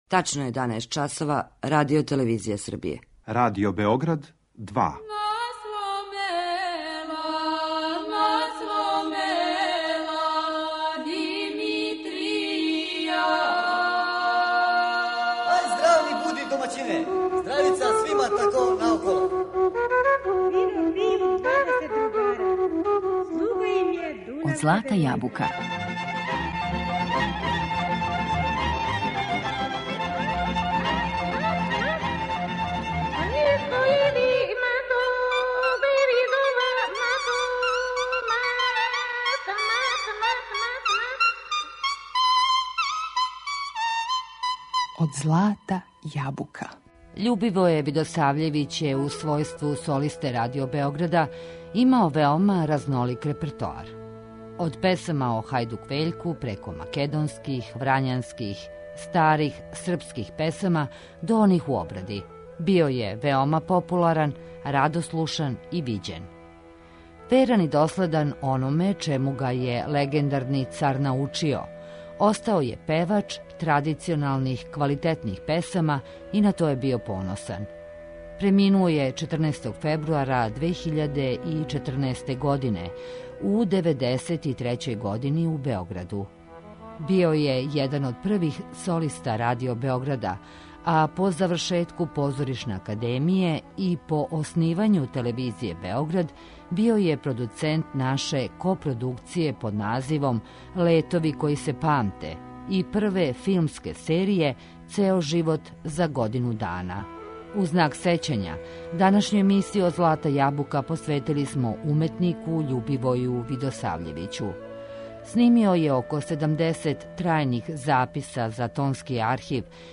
певач традиционалних песама
Веран и доследан ономе чему га је легендарни Царевац научио, био је певач квалитетних традиционалних песама и на то је био поносан.